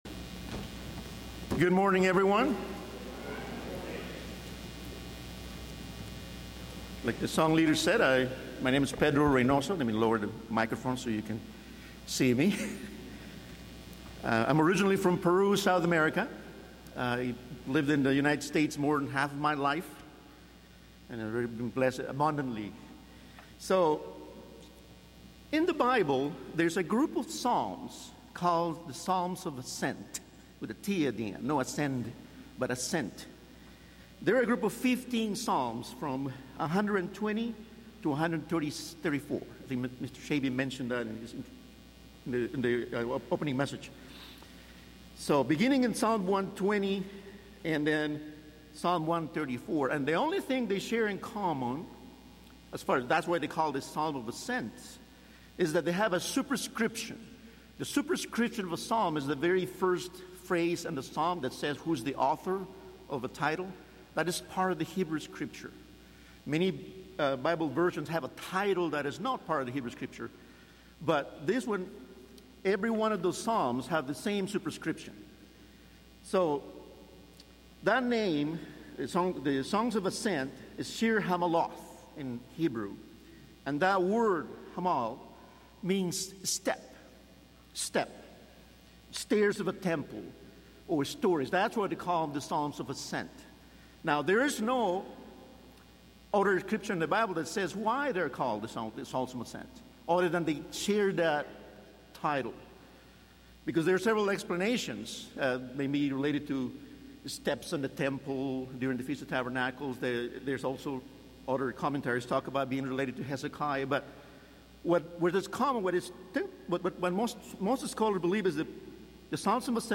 This sermon was given at the Gatlinburg, Tennessee 2023 Feast site.